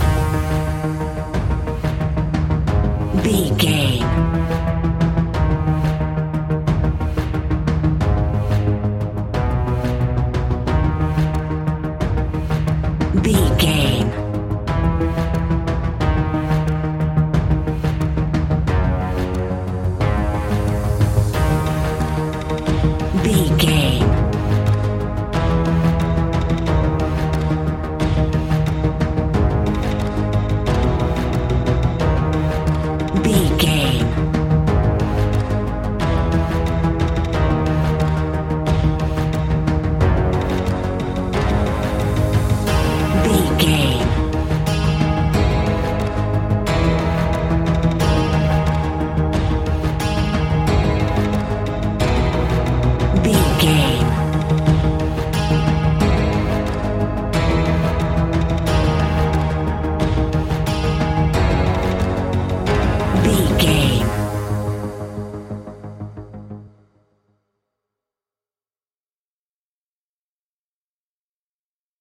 Aeolian/Minor
G#
ominous
dark
eerie
drums
percussion
synthesiser
electronic music